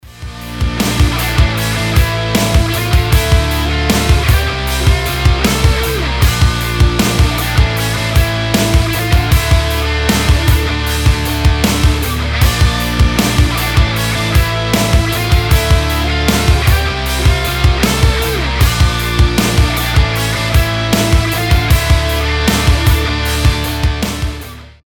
• Качество: 320, Stereo
без слов
Alternative Rock
Pop Rock
Рок проигрыш без слов